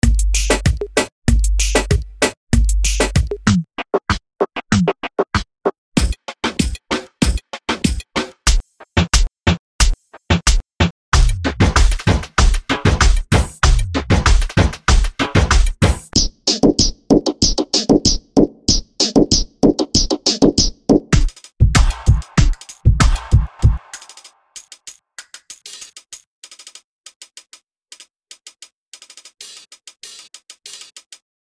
REGGAETON DEMBOWS LOOPS TO STEP YOUR GAME UP ON THE REGGAETON PRODUCTION